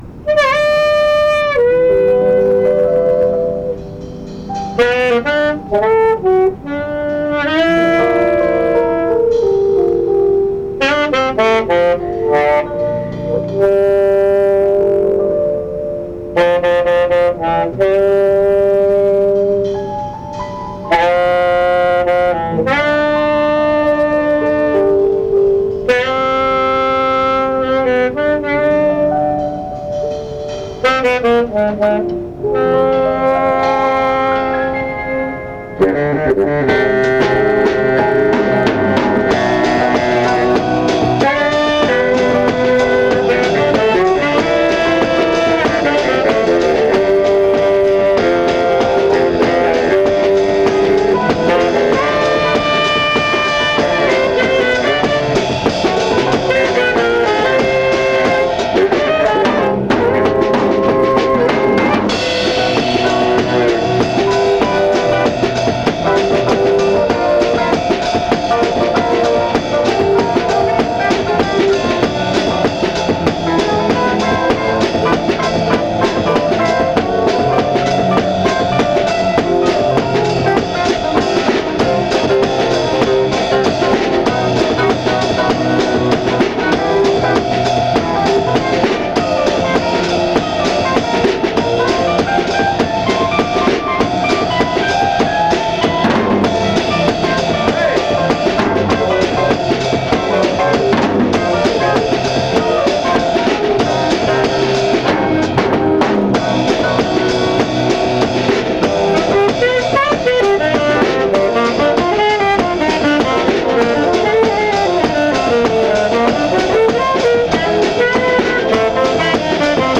Style latin, bossa
Batterie un peu envahissante, ne pas trop en tenir compte